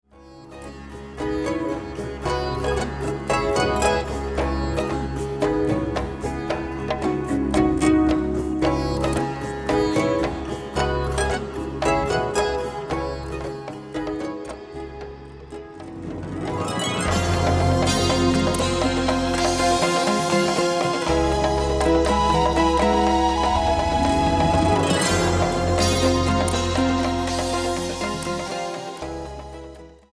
electroacoustic pedal harp, gu-cheng & more...
Recorded and mixed at the Sinus Studios, Bern, Switzerland